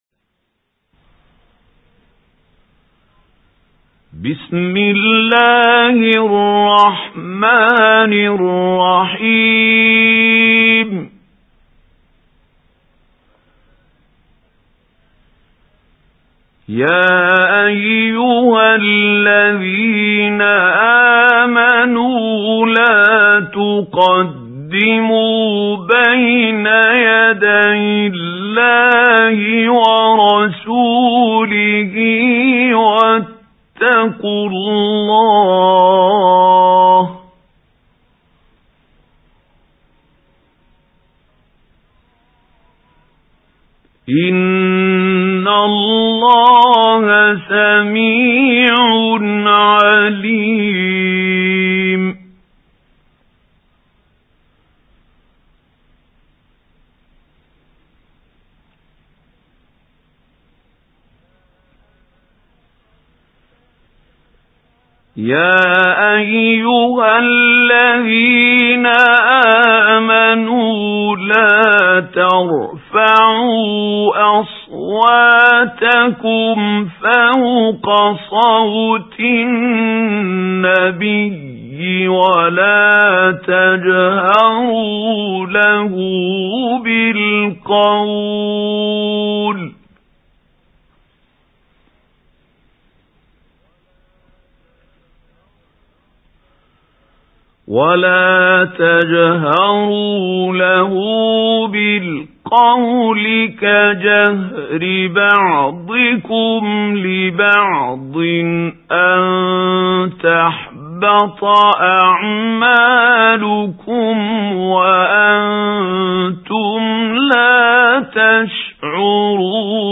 سُورَةُ الحِجۡرِات بصوت الشيخ محمود خليل الحصري